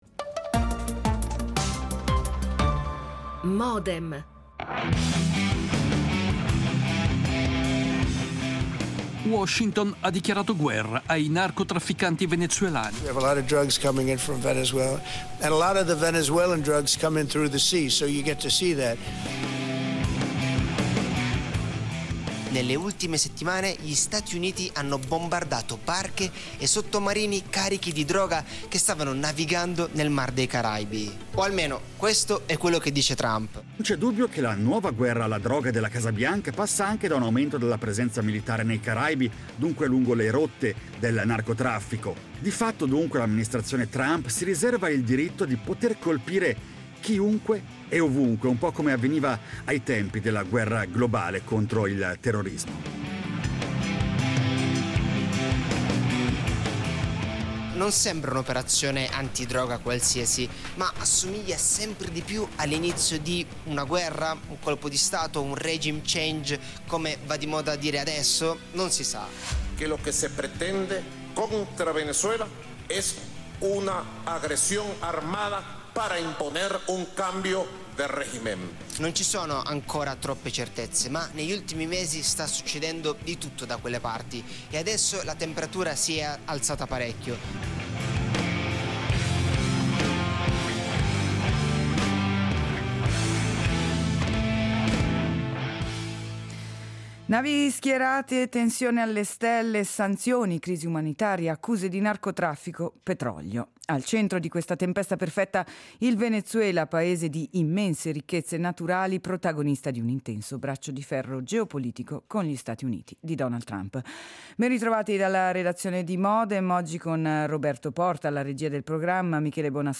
L'attualità approfondita, in diretta, tutte le mattine, da lunedì a venerdì